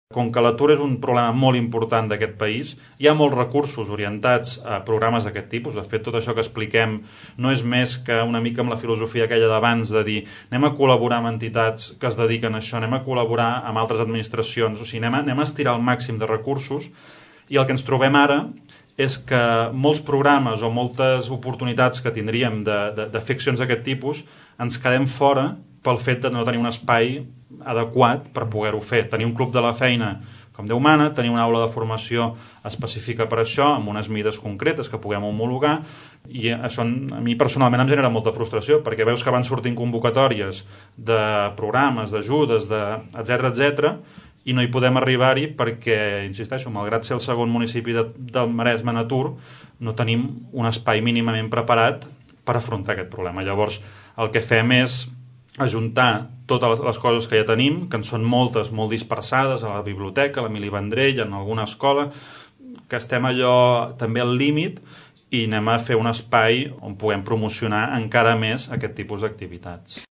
La voluntat que es busca amb les noves oficines és concentrar en un únic espai, tots els serveis que ofereix l’àrea i combatre de manera més efectiva les elevades xifres d’atur al nostre municipi. Escoltem el regidor de promoció econòmica i ocupació, Marc Unió.